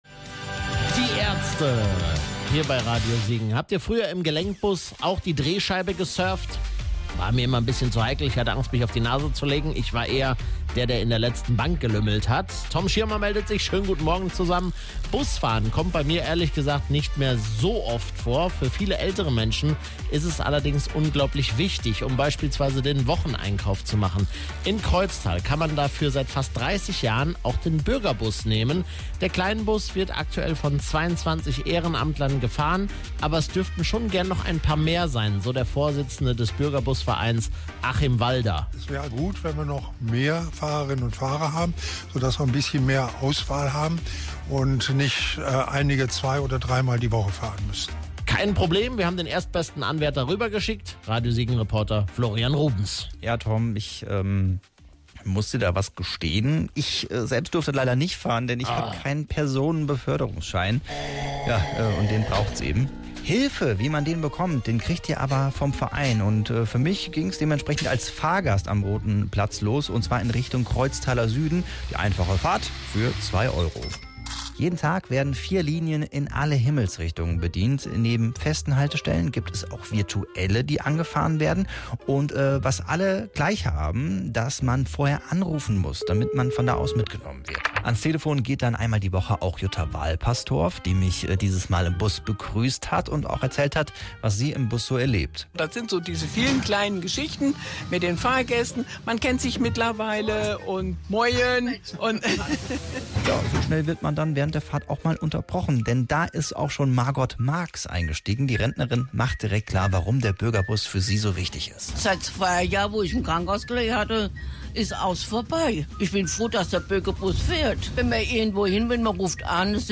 Radio Siegen hat sich mal unter Fahrgästen umgehört.